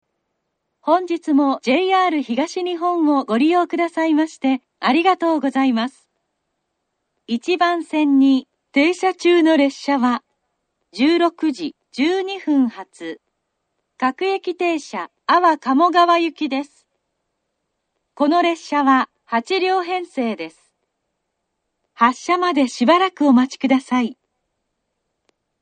１番線出発予告放送 16:12発各駅停車安房鴨川行（８両）の放送です。
kazusa-ichinomiya-1bannsenn-shuppatsu.mp3